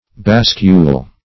Bascule \Bas"cule\ (b[a^]s"k[-u]l), n. [F., a seesaw.]